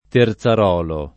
terzarolo [ ter Z ar 0 lo ]